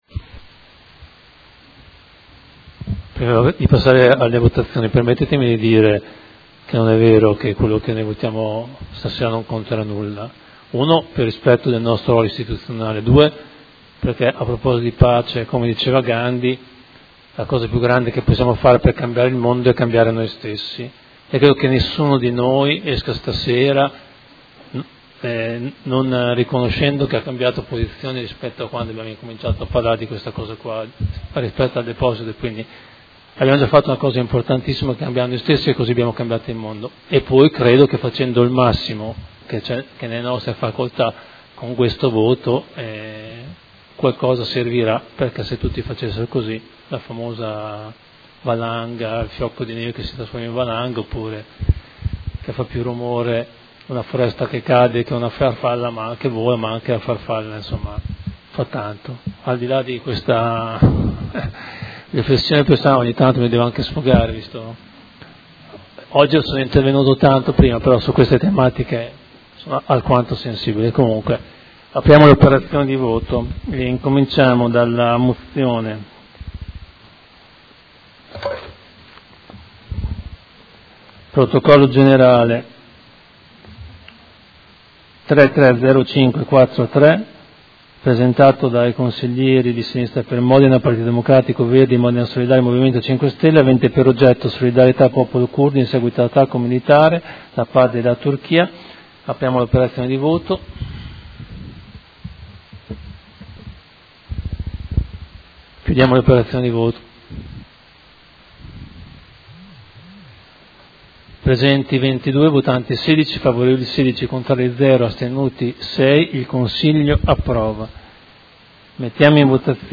Presidente — Sito Audio Consiglio Comunale
Presidente
Seduta del 14/11/2019. Interviene e mette ai voti Ordine del Giorno presentato da Consiglieri dei gruppi Sinistra Per Modena - Partito Democratico - Verdi - Modena Solidale - Movimento 5 Stelle avente per oggetto "Solidarietà al popolo curdo in seguito all'attacco militare da parte della Turchia nel territorio del Rojava" e Ordine del Giorno presentato dai Consiglieri Baldini, De Maio, Bertoldi, Bosi e Santoro (Lega Modena) avente per oggetto "Solidarietà alla popolazione civile del nord della Siria a seguito dell'attacco militare da parte della Turchia".